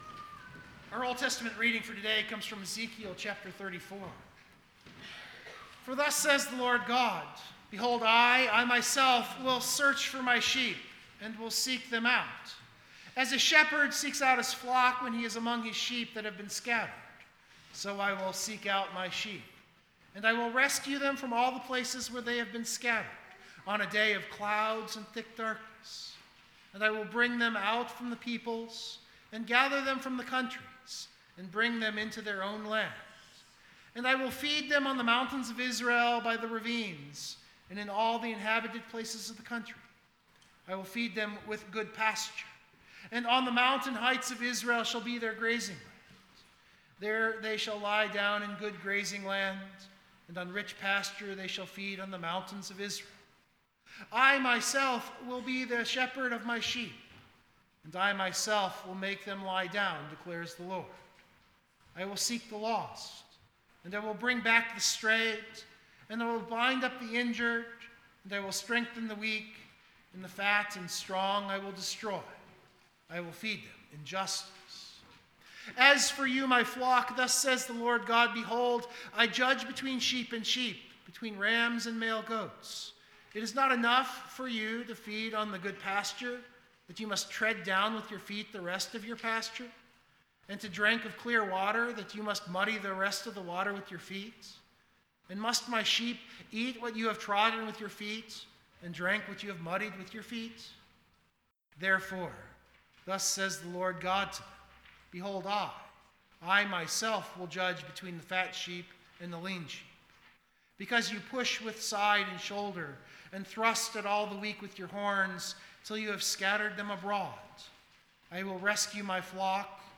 This sermon attempted to spotlight that secondary thread for the purpose of understanding the day.
Jesus Sinners Doth Receive was the Hymn of the Day on the primary theme, but I left in our final hymn.